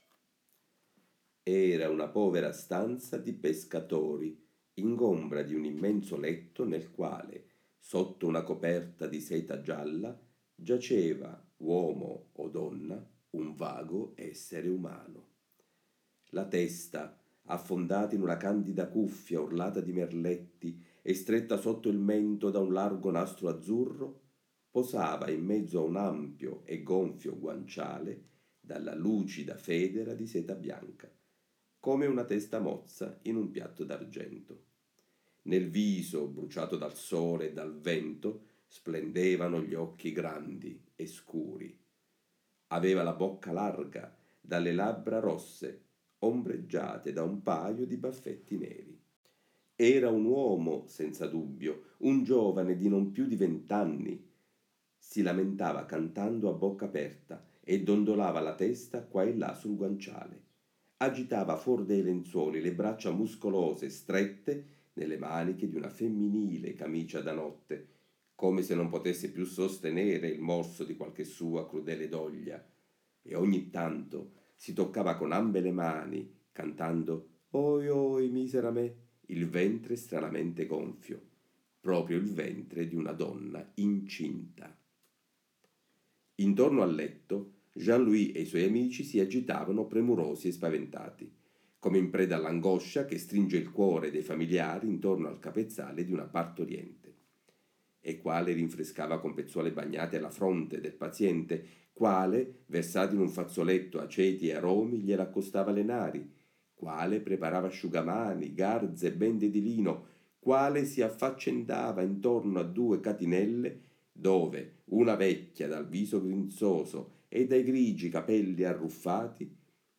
A dimostrazione, l’audiolettura propone un brano da La pelle di Curzio Malaparte, romanzo del 1949, ambientato nella Napoli alla fine della Seconda guerra mondiale, dove si descrive il rito della Figliata del femminiello, ovverosia un finto parto, dai toni arcaici, misteriosi e insieme comici, osceni; una sorta di grottesca cerimonia di fecondità officiata e recitata da quei travestiti omosessuali tipicamente partenopei.